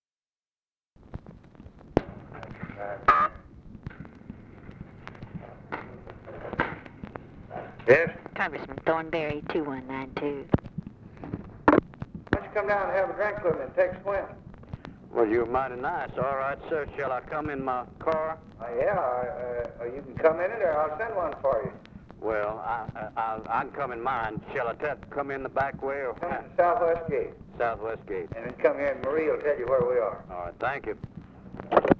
Conversation with HOMER THORNBERRY, December 3, 1963
Secret White House Tapes